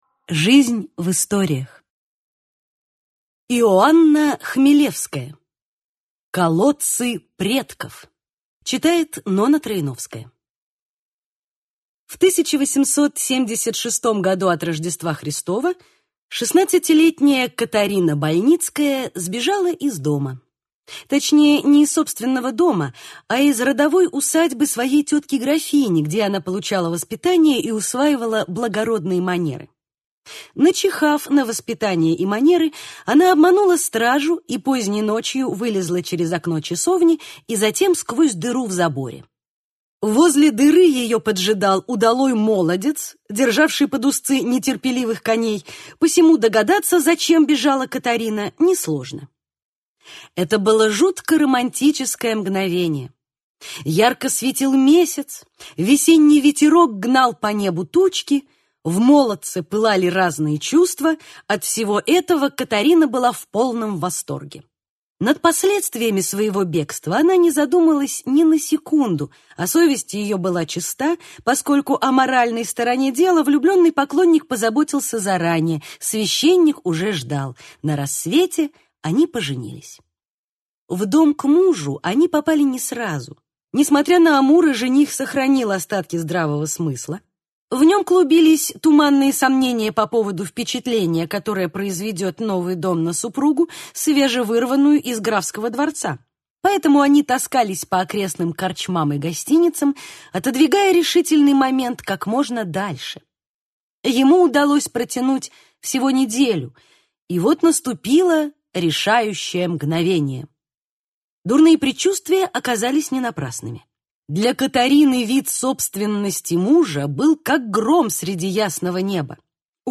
Аудиокнига Колодцы предков | Библиотека аудиокниг
Прослушать и бесплатно скачать фрагмент аудиокниги